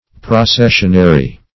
Processionary \Pro*ces"sion*a*ry\, a. [Cf. LL. processionarius,